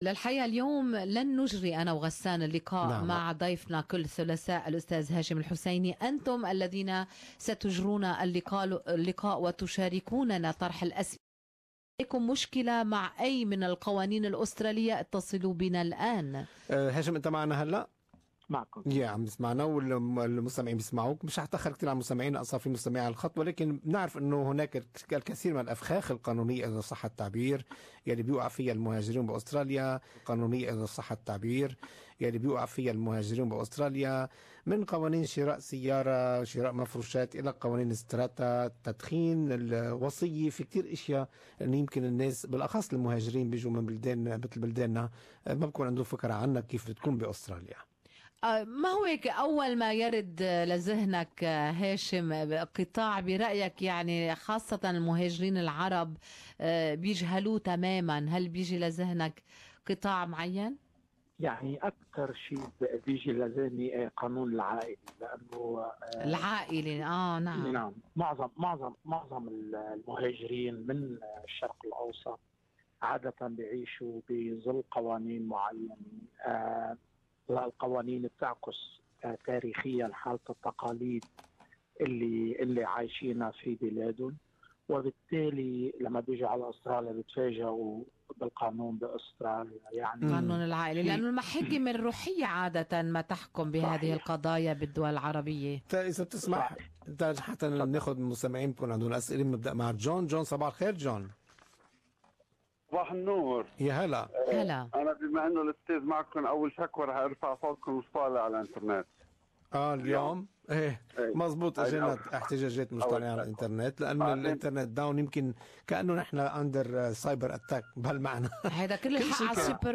Talkback: Answers to Some Australian Law Questions